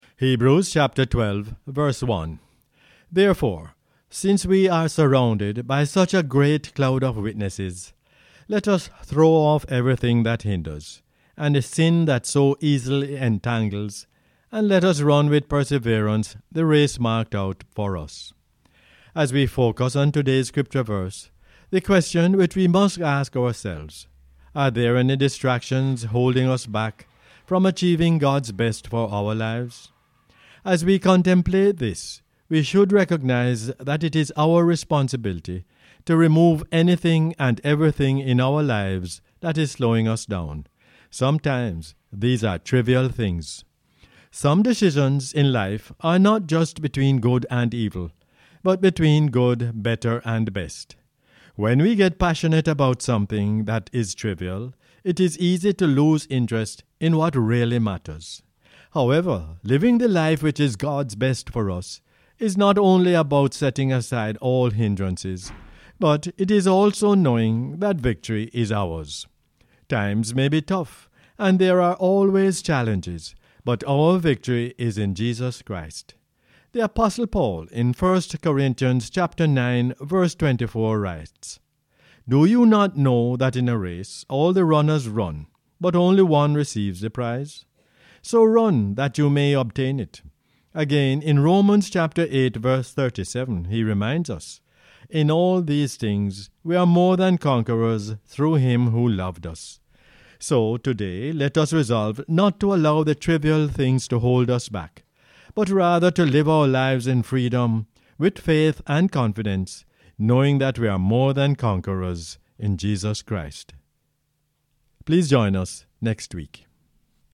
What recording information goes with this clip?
Hebrews 12:1 is the "Word For Jamaica" as aired on the radio on 2 October 2020.